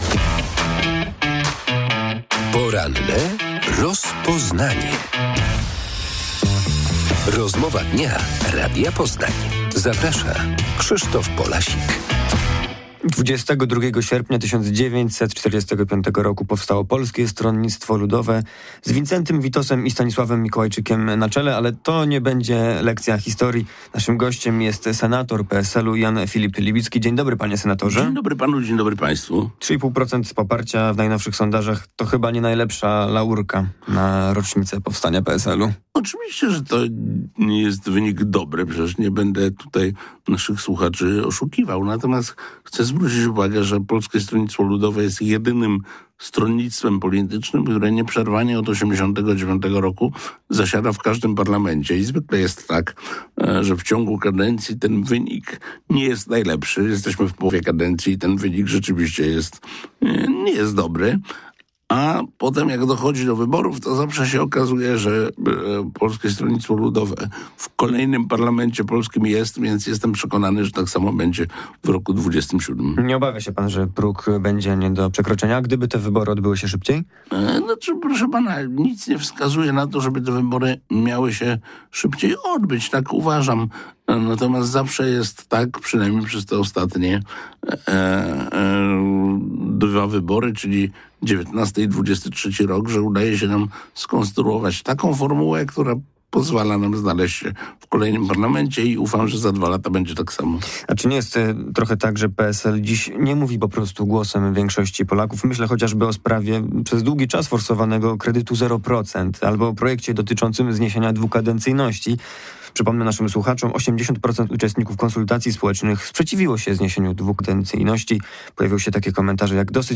80 lat temu powstało Polskie Stronnictwo Ludowe. Gościem porannej rozmowy Radia Poznań jest senator PSL Jan Filip Libicki